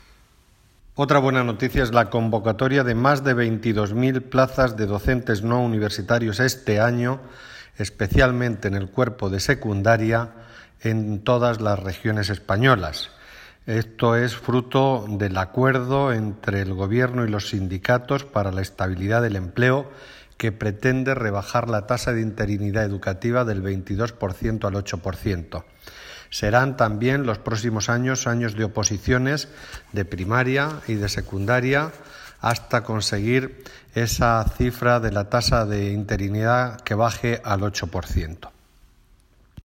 Declaración del secretario de Estado de Educación, Formación Profesional y Universidades, Marcial Marín.